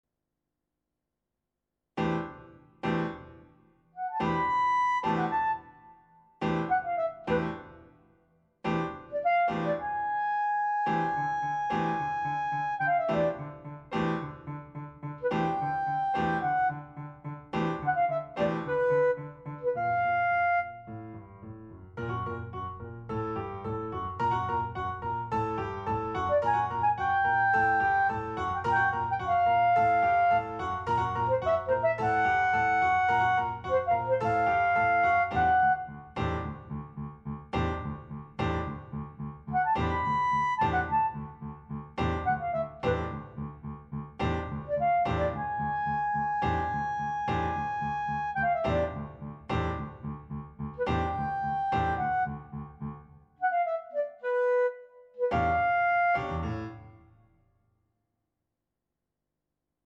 Instrumentation: Clarinet, pno